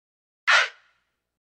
Звуки битовой музыки
Здесь вы найдете мощные ритмы, минималистичные мелодии и экспериментальные звуковые текстуры.